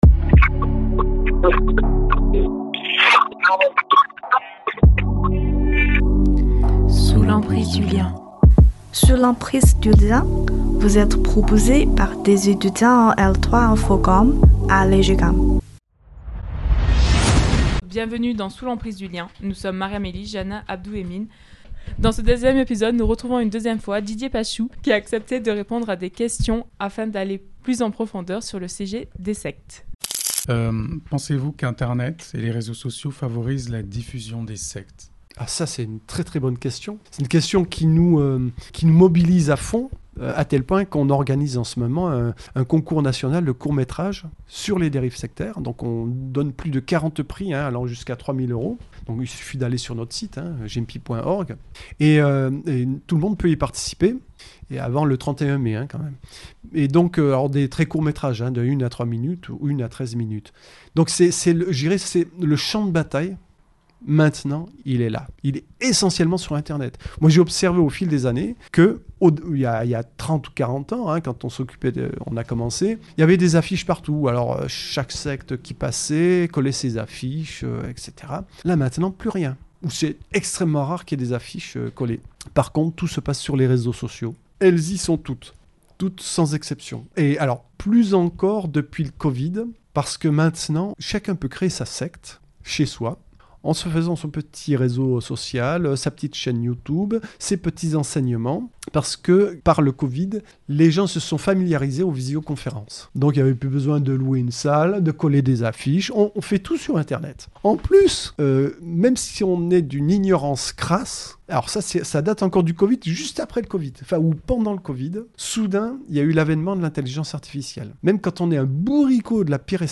À travers un échange clair et accessible avec un invité spécialiste du sujet nous revenons avec lui sur la définition d’une secte, les moments clés qui ont marqué l’histoire des sectes dans le monde et les mécanismes d’emprise qui permettent à ces groupes d’attirer puis de retenir leurs membres."
Cette équipe réunit quatre étudiants en Information-Communication à l’EJCAM – Aix-Marseille Université